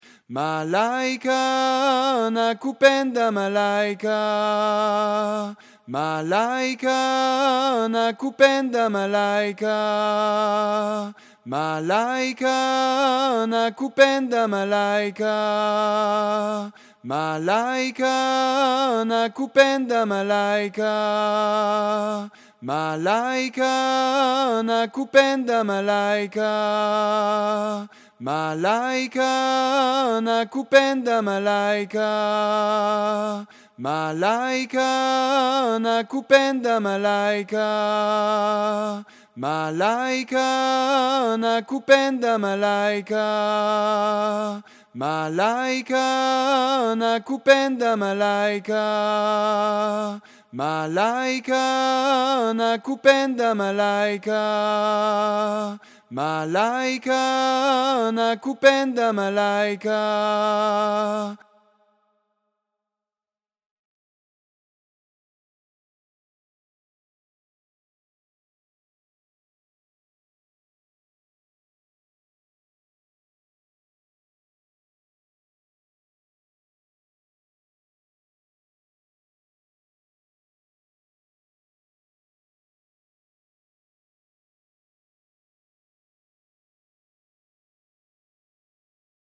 La voix 1 et son playback :